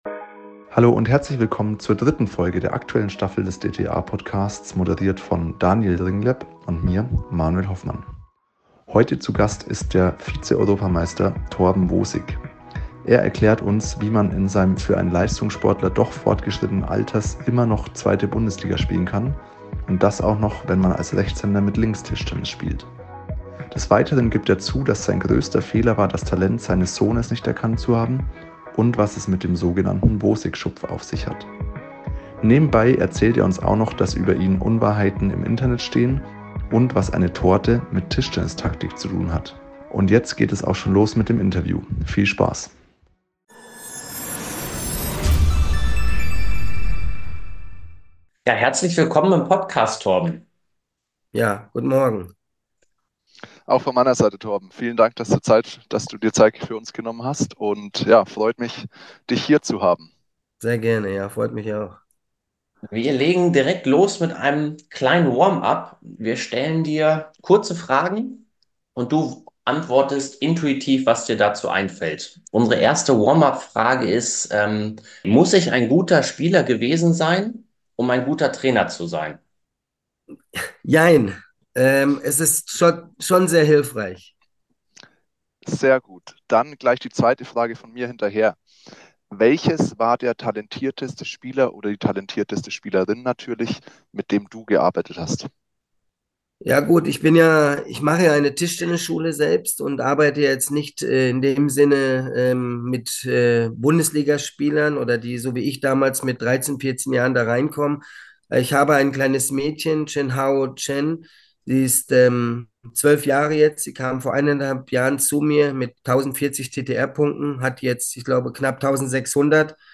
Beschreibung vor 1 Jahr In der dritten Episode der aktuellen Staffel des DTTA-Podcasts haben wir das Privileg, den Vize-Europameister Torben Wosik zu interviewen. Er teilt mit uns die faszinierende Geschichte, wie er als Rechtshänder das Tischtennisspiel mit der linken Hand erlernte und beleuchtet dabei die Unwahrheiten, die im Internet über ihn kursieren.